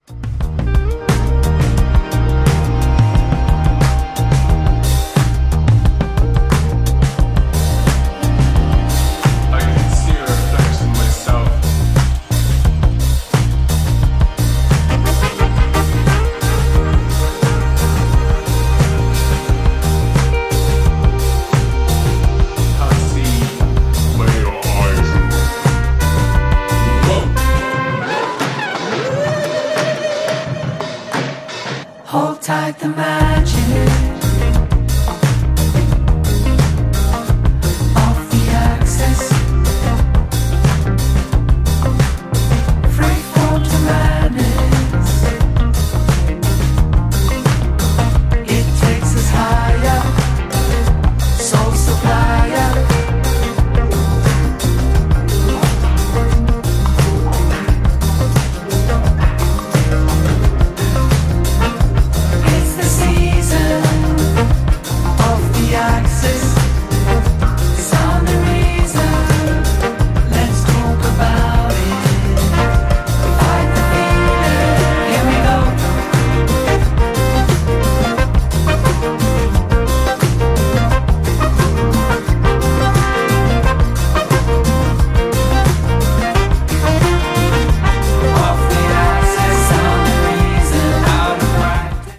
ジャンル(スタイル) JAZZ